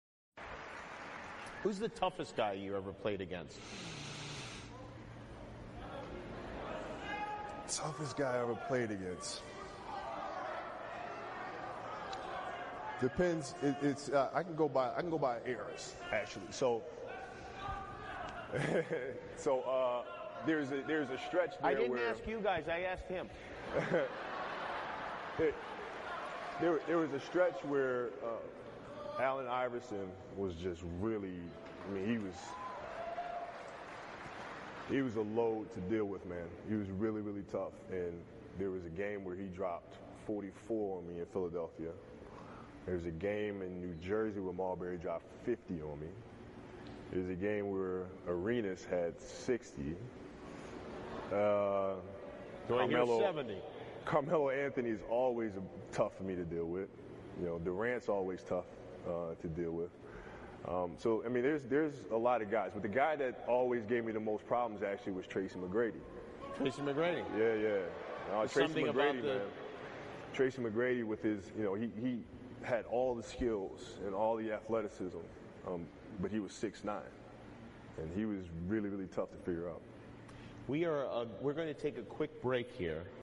篮球英文堂 第130期:科比访谈 生涯最强的对手是?